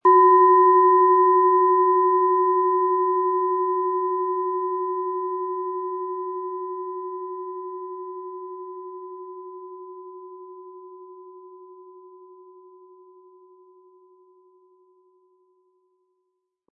Planetenton 1
Von Meisterhand hergestellte Planetenton-Klangschale Jupiter.